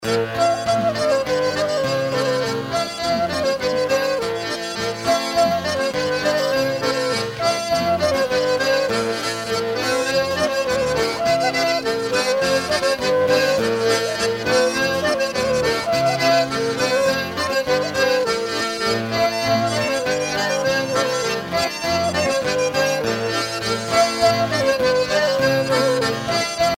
Usage d'après l'analyste gestuel : danse ;
Catégorie Pièce musicale éditée